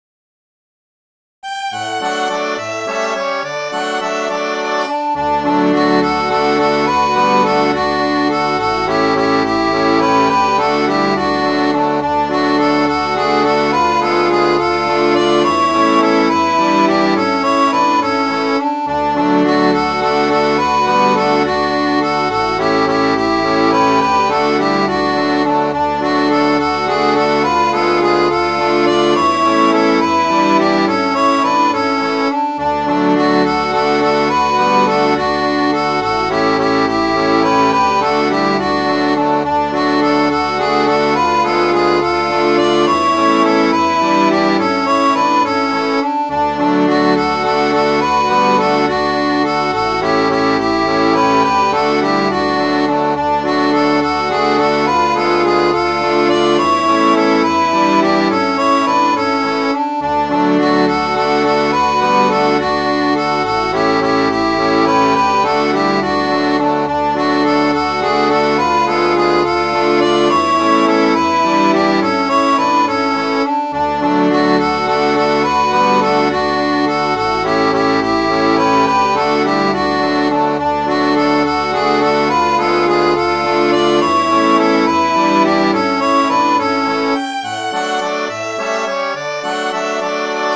homewardb.mid.ogg